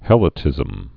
(hĕlə-tĭzəm)